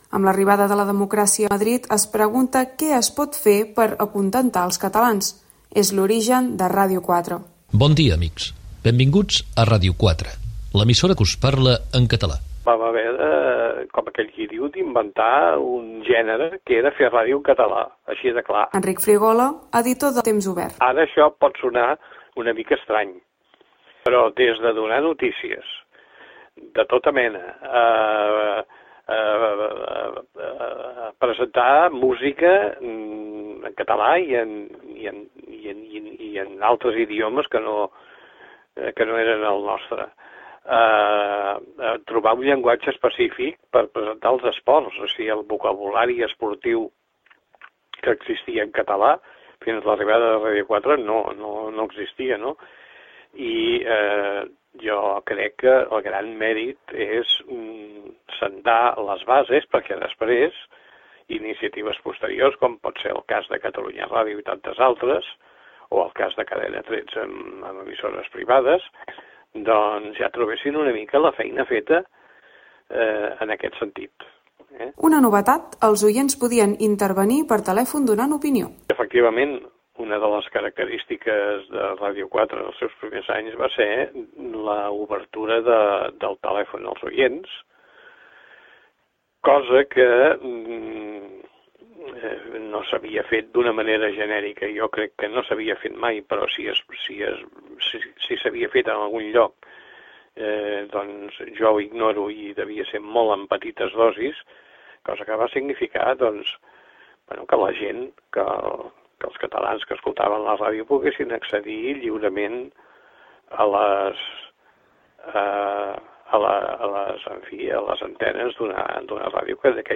Gènere radiofònic Divulgació